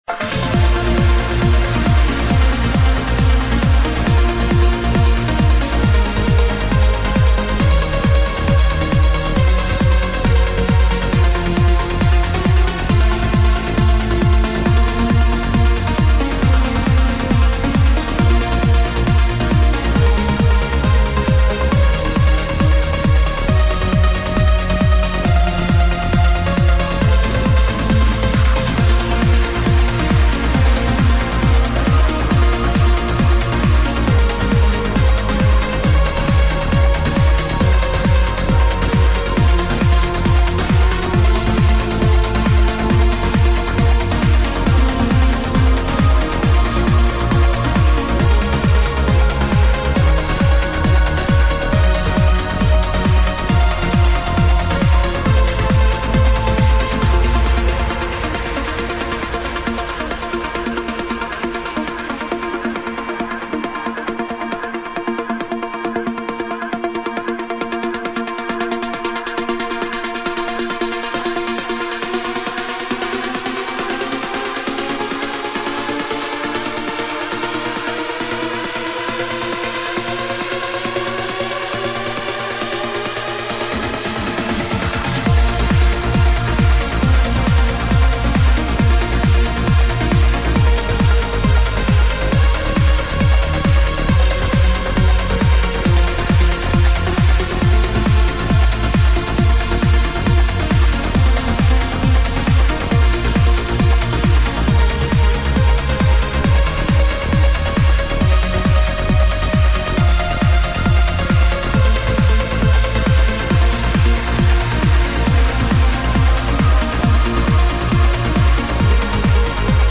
[ TRANCE | PROGRESSIVE HOUSE ]